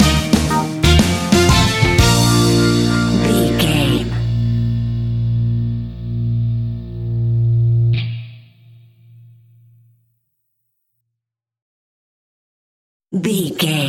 Hot summer sunshing reggae music for your next BBQ!
Ionian/Major
B♭
Slow
instrumentals
laid back
chilled
off beat
drums
skank guitar
hammond organ
percussion
horns